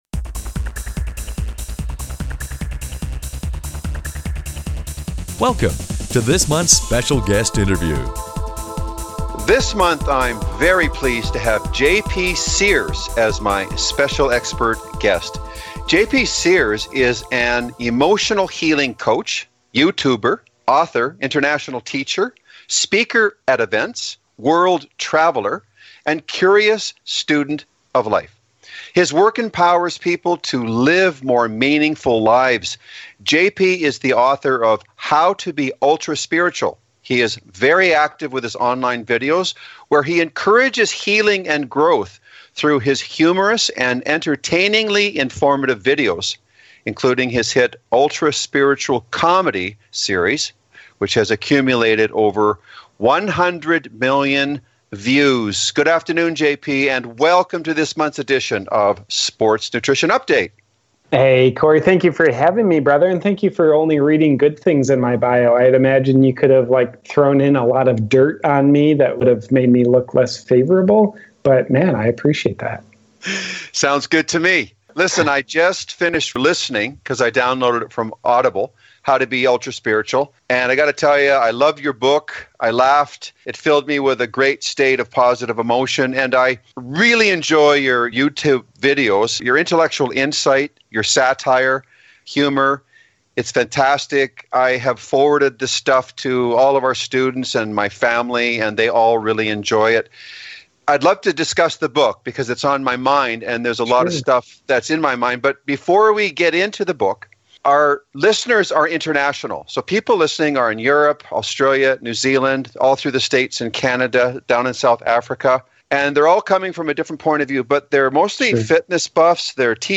Special Guest Interview Volume 16 Number 7 V16N7c - Guest JP Sears is an emotional healing coach, YouTuber, author, international teacher, speaker at events, world traveler, and curious student of life.